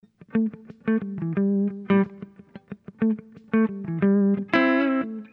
Loops guitares rythmique- 100bpm 3
Guitare rythmique 56